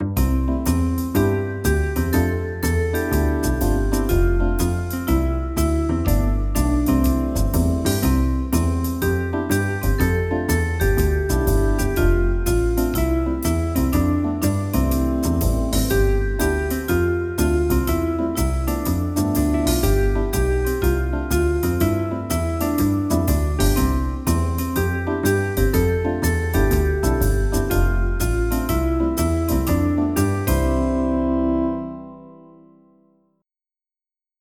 MIDI Music File
swing=62